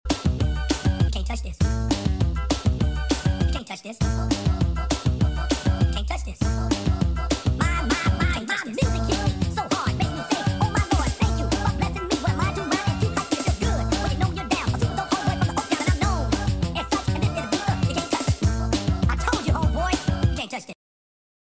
#90s